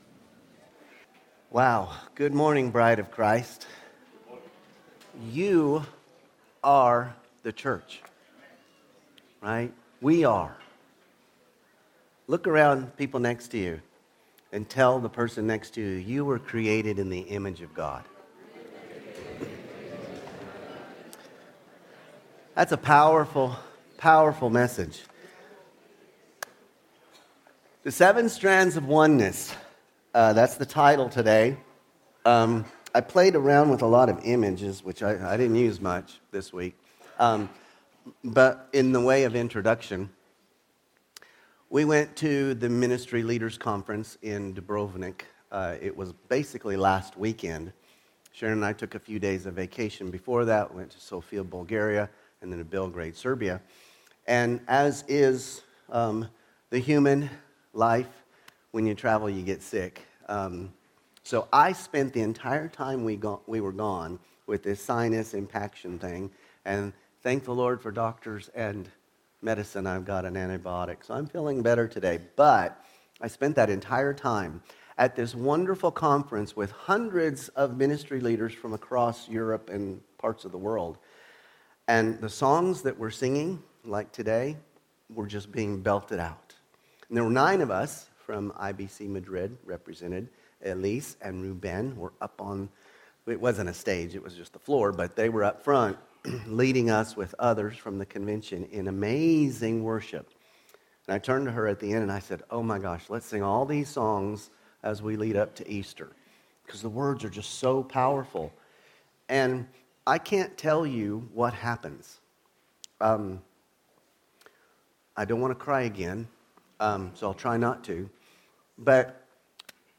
Sermons – Page 20 – Immanuel Baptist Church | Madrid